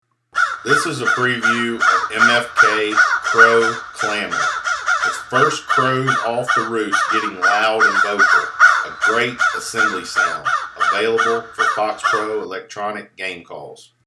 MFK Crow Clammer – 16 bit
Recorded with the best professional grade audio equipment MFK strives to produce the highest